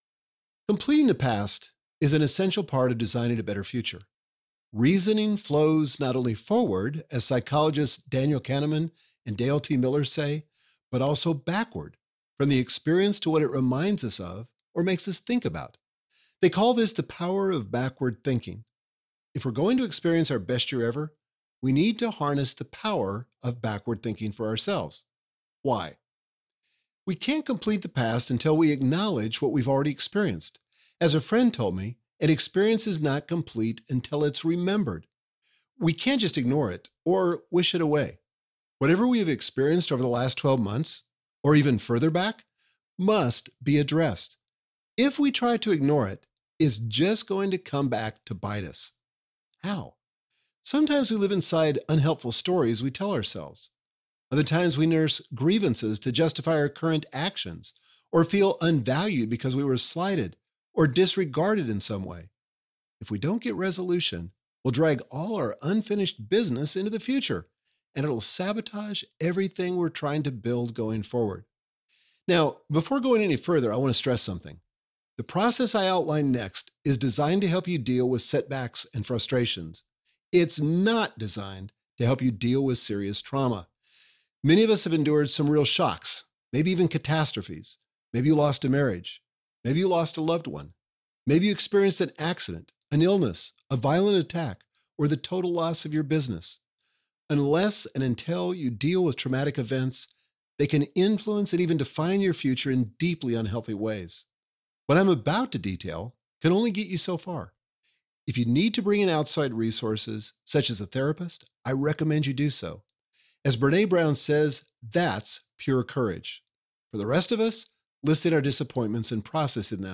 Your Best Year Ever Audiobook
Narrator
Michael Hyatt
4.55 Hrs. – Unabridged